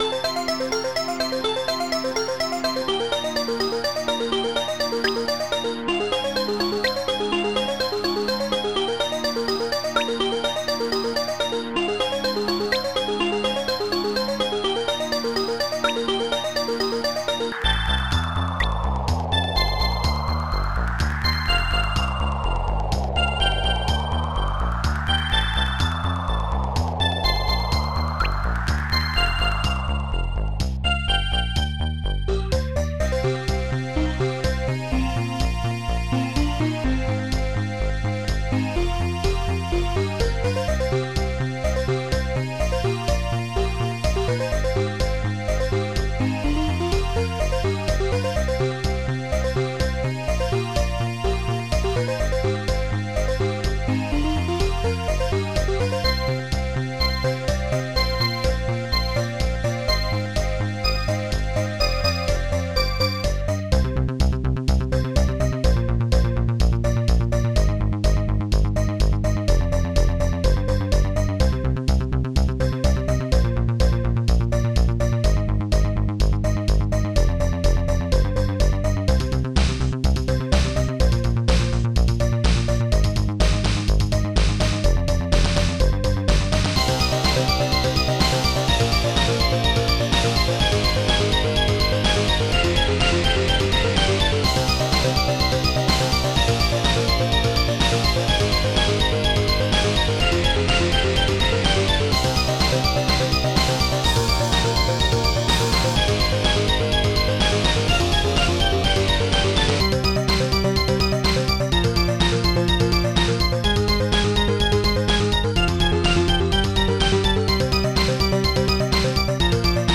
st-02:Bass12
st-01:SoftBass
st-02:Snare1
st-09:guitar-elec1
st-09:Guitar-Soft1